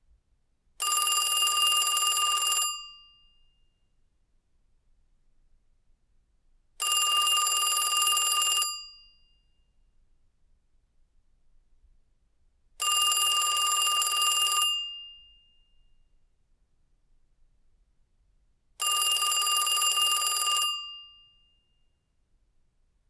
phone_ringing.mp3